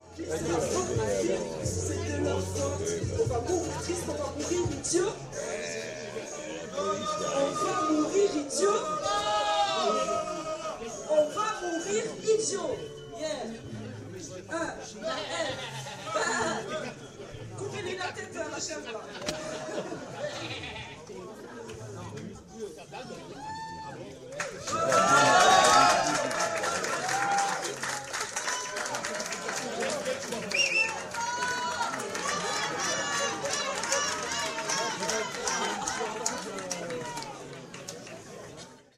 描述：实验性的小故事舞蹈音乐
标签： 出问题 舞蹈 常规
声道立体声